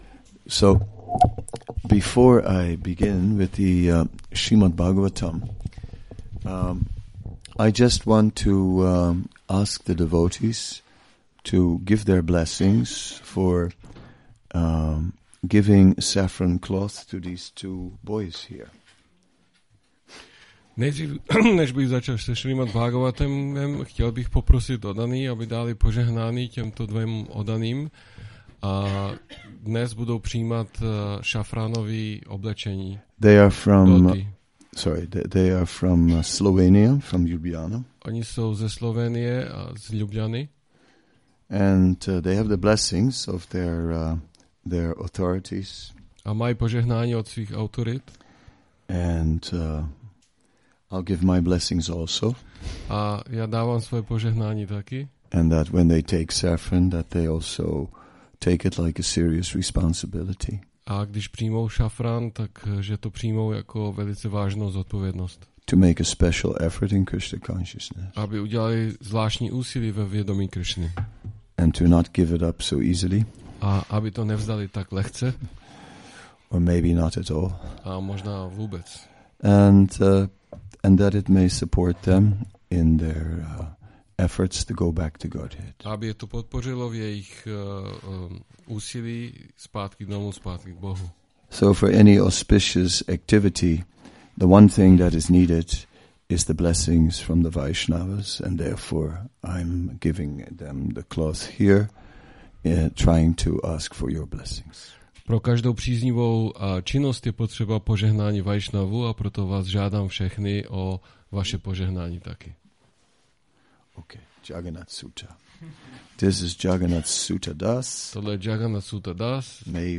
Přednáška SB-10.3.21 – Šrí Šrí Nitái Navadvípačandra mandir